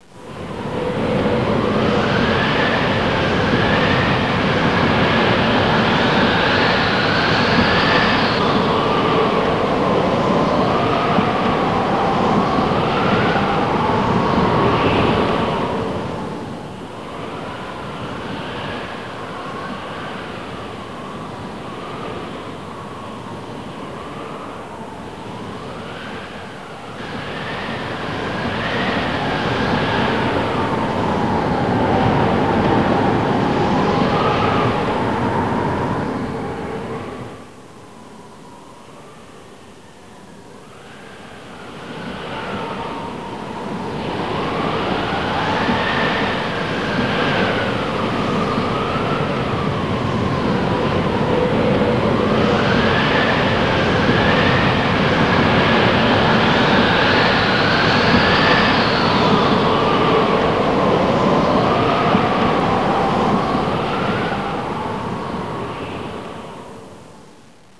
cliff_wind.wav